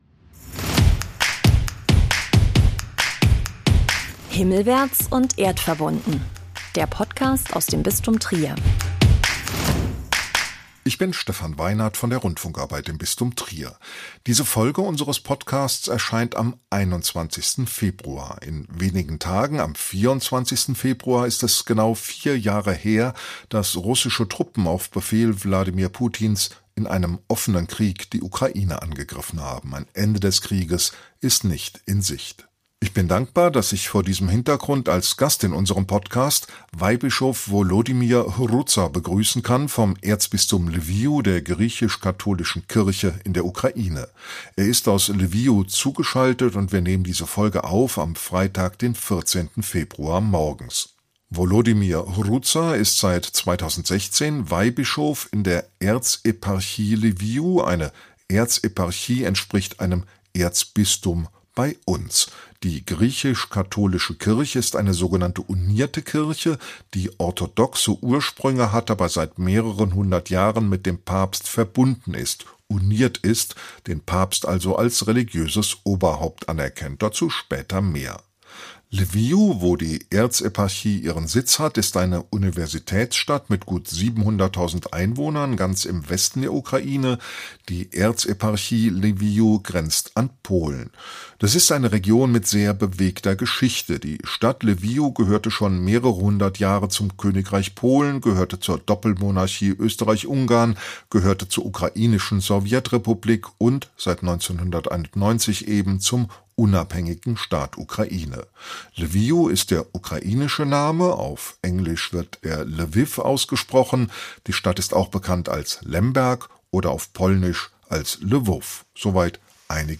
Lwiw (Lemberg) in der Westukraine im Krieg: Weihbischof Volodymyr Hrutsa erzählt vom Alltag trotz Bedrohung, Trauerseelsorge, einem Begegnungscafé, dem überfüllten Soldatenfriedhof und Konzerten im Bischofsgarten.